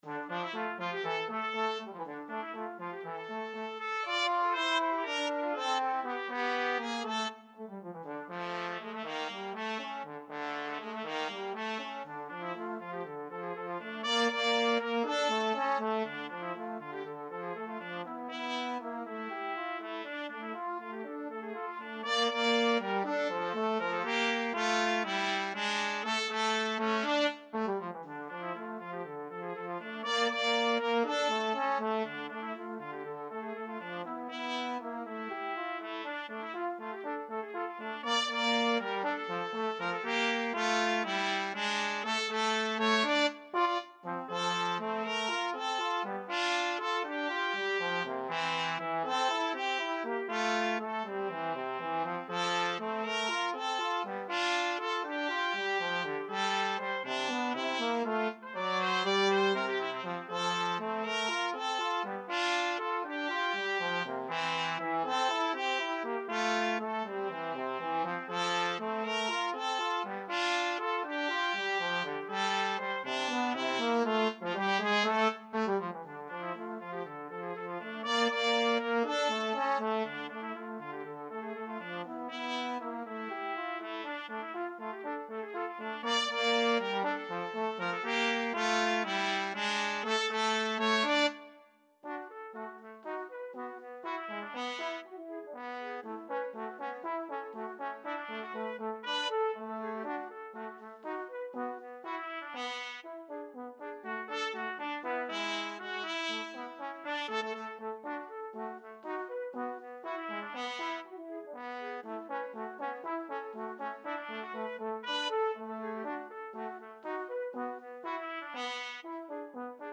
2/4 (View more 2/4 Music)
Allegretto Misterioso = 120
Classical (View more Classical Trumpet-Trombone Duet Music)